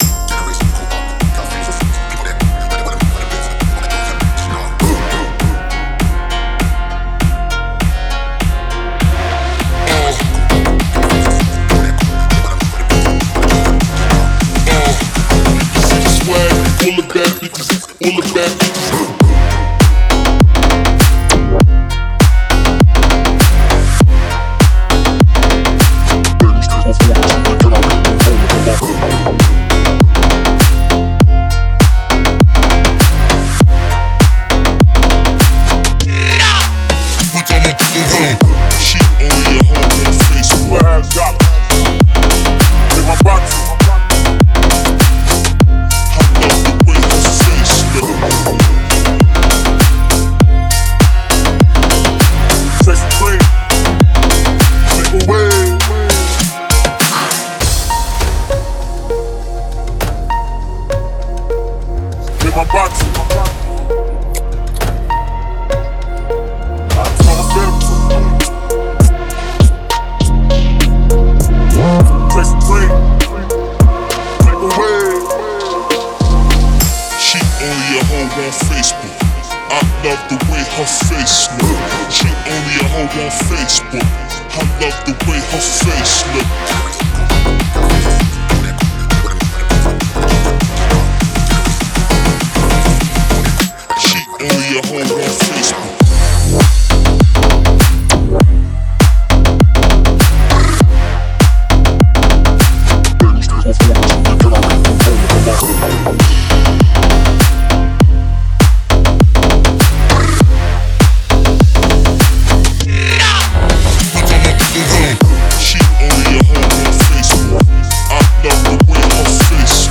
Клубная музыка
клубные треки